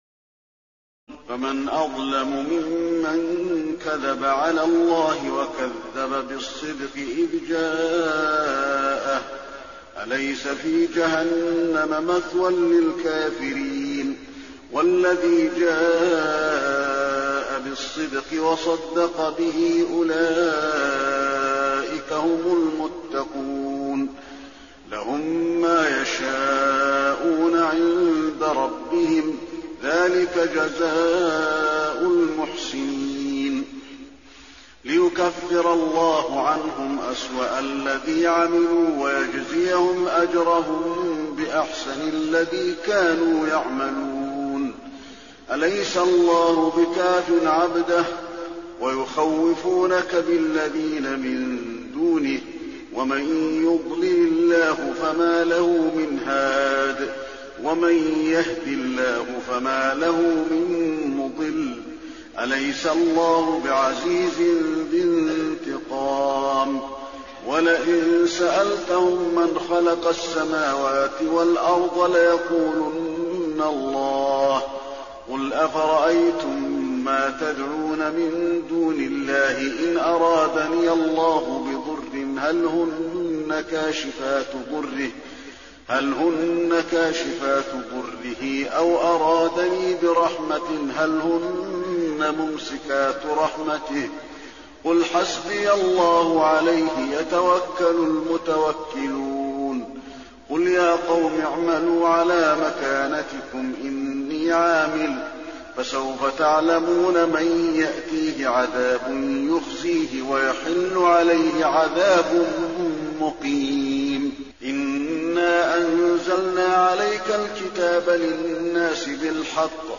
تراويح ليلة 23 رمضان 1422هـ من سور الزمر(32-75) وغافر (1-37) Taraweeh 23 st night Ramadan 1422H from Surah Az-Zumar and Ghaafir > تراويح الحرم النبوي عام 1422 🕌 > التراويح - تلاوات الحرمين